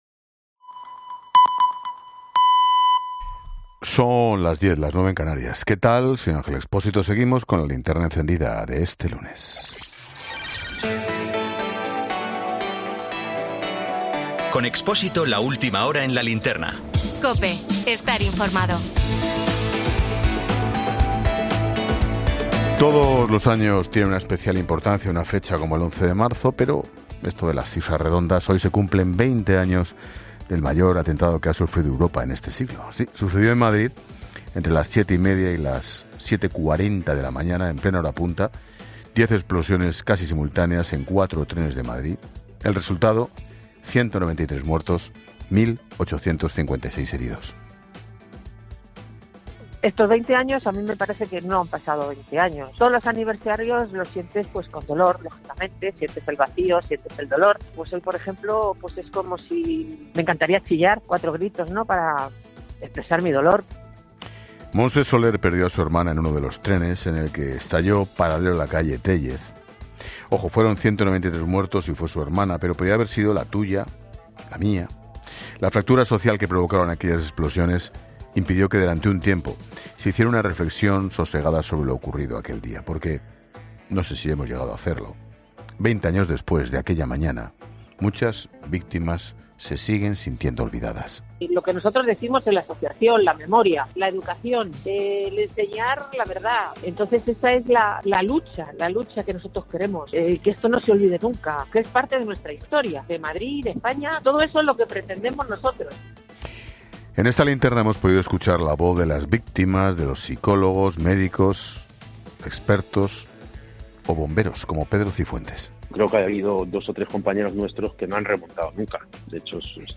Boletín 22.00 horas del 11 de marzo de 2024 La Linterna